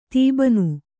♪ tībasu